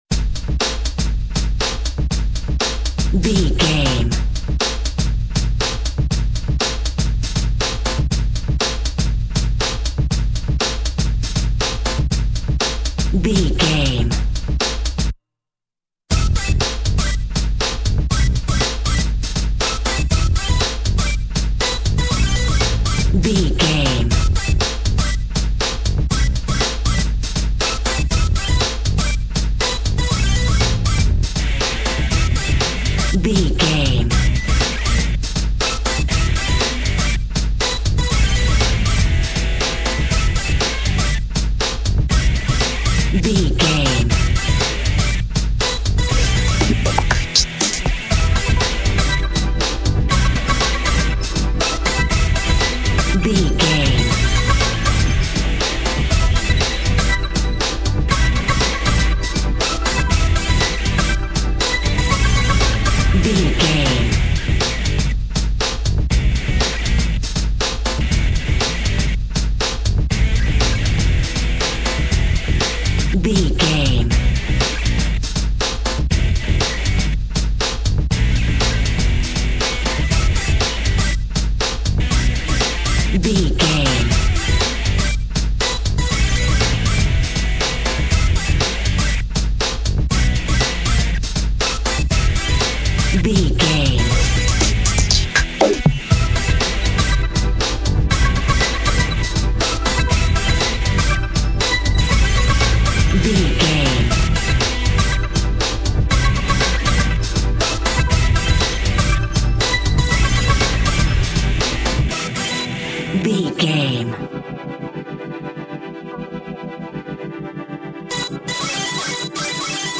Aeolian/Minor
energetic
dark
hypnotic
drum machine
synthesiser
techno
bass synth